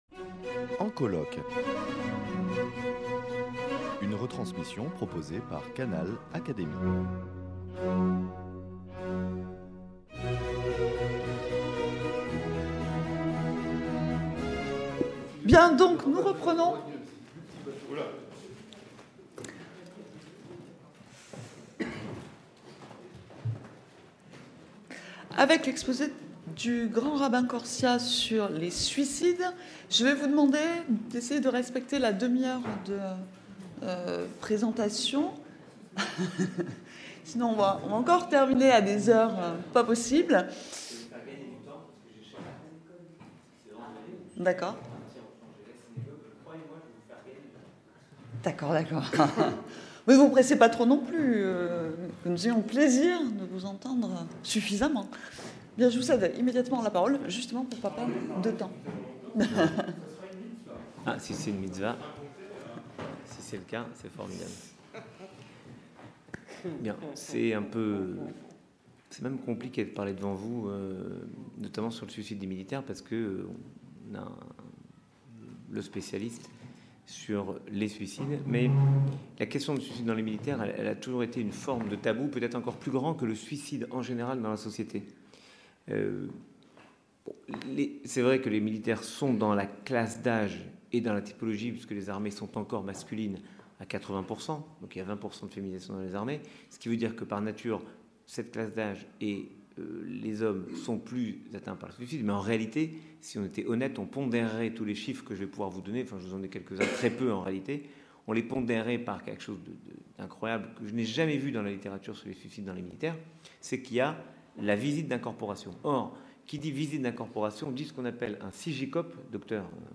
prononcée le 17 octobre 2014 lors des journées d’études « Guerre et armée » organisées dans le cadre du programme de recherche « Guerre et société »